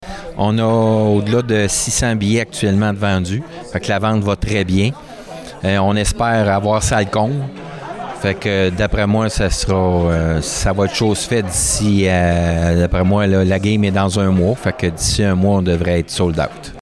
En conférence de presse lundi